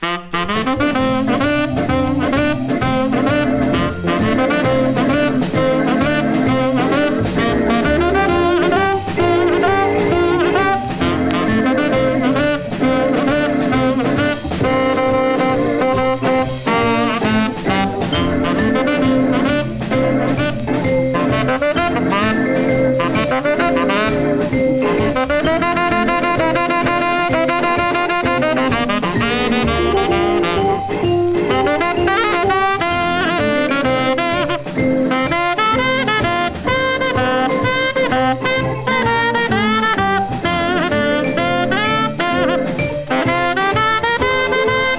Jazz, R&B　France　12inchレコード　33rpm　Mono